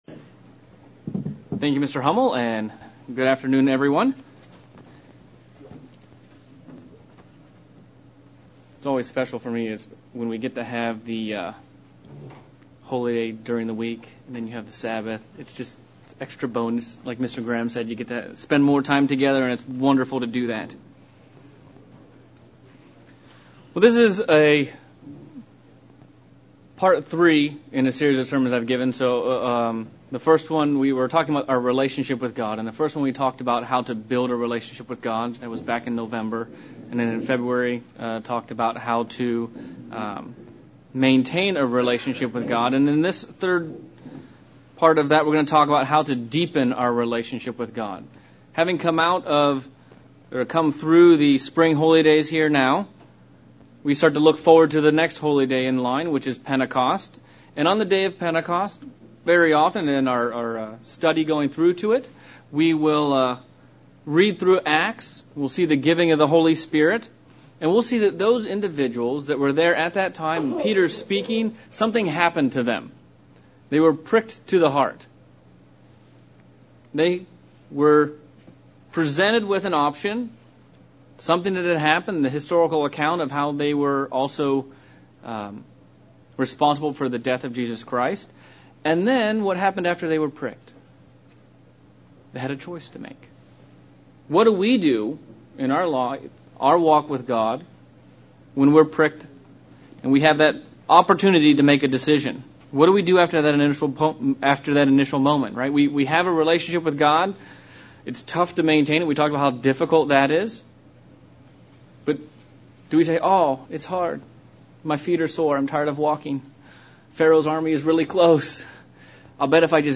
Part 3 of a sermon series on building our Relationship with God. How we can deepen this relationship and draw closer to God.